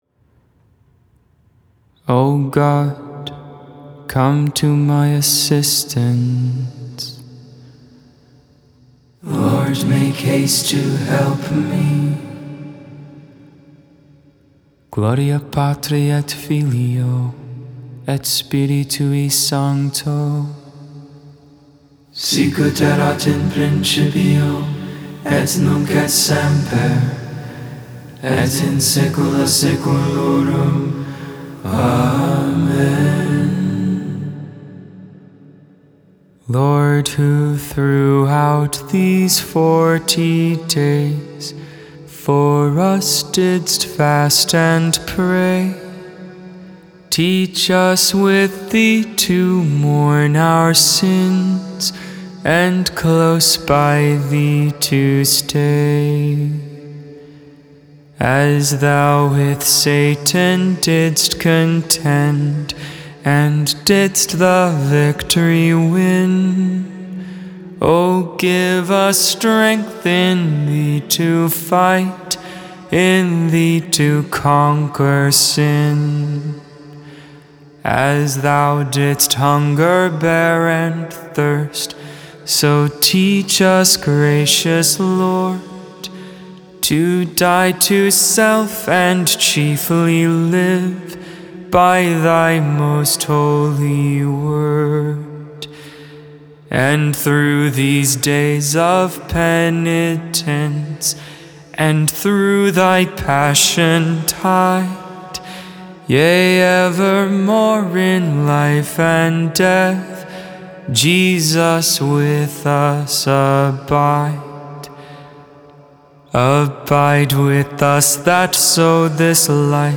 Lauds, Morning Prayer for The Thursday following Ash Wednesday, March 3rd, 2022.